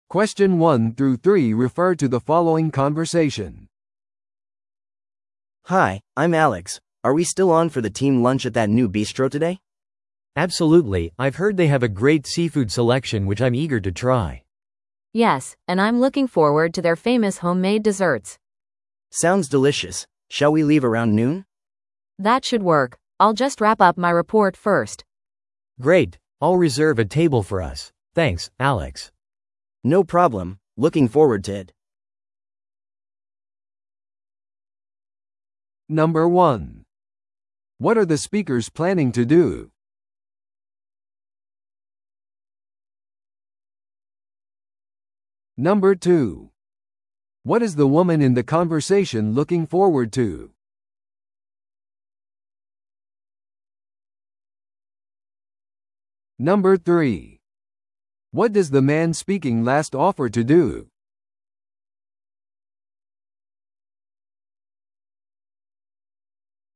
No.2. What is the woman in the conversation looking forward to?
No.3. What does the man speaking last offer to do?